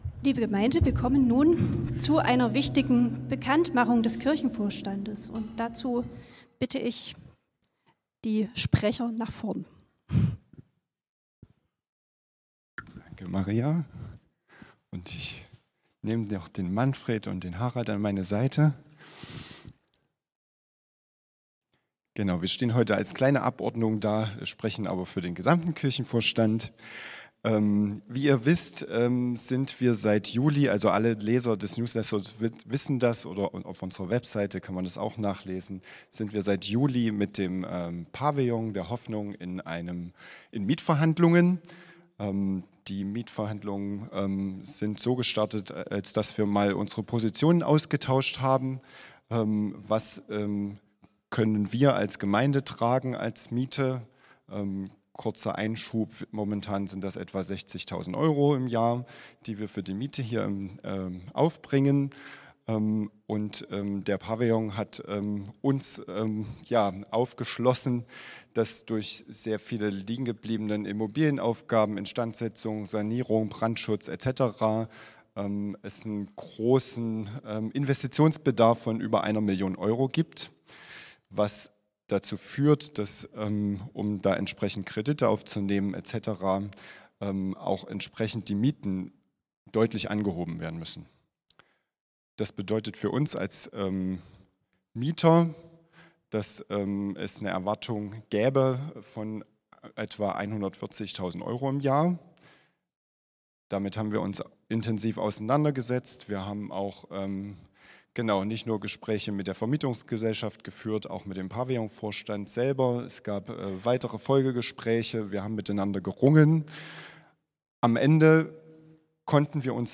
Hier stellen wir Dir die Audiodateien aus dem Gottesdienst zur Verfügung.